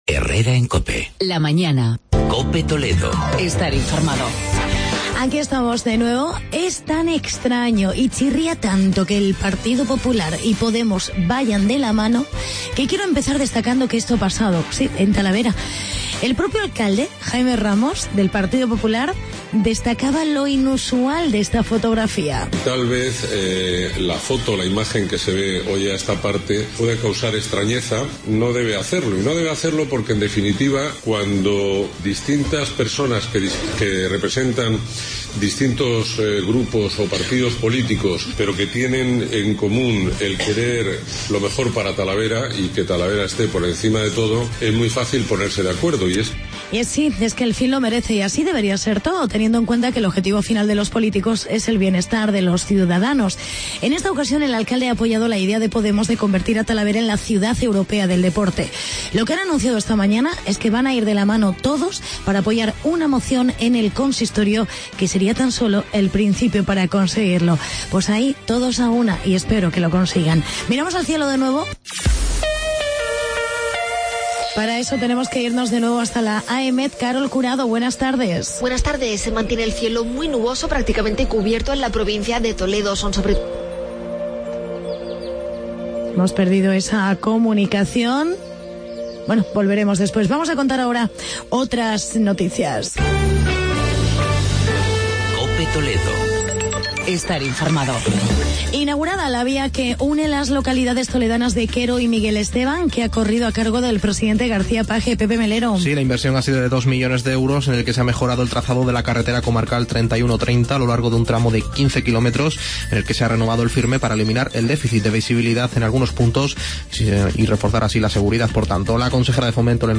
AUDIO: Presenta: Actualidad y Reportaje sobre la participación de personas mayores. Reportaje sobre el Campeonato de España de Ornitología.